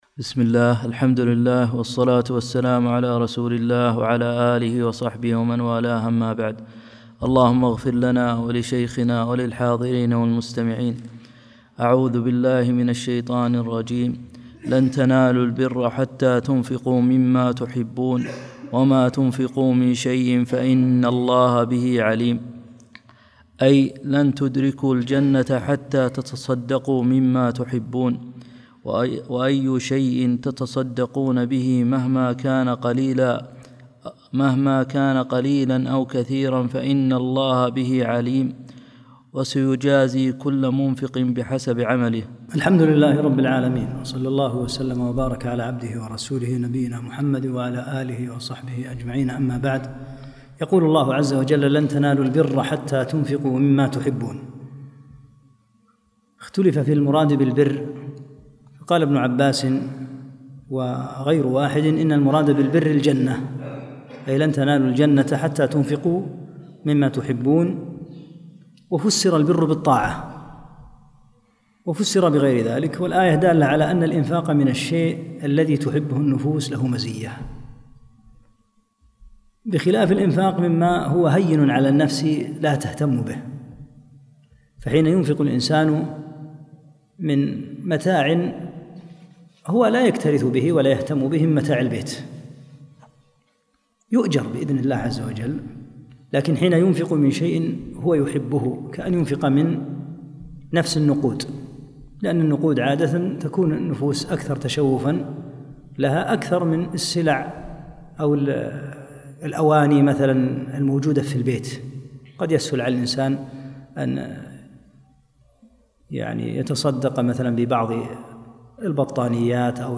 11 - الدرس الحادي عشر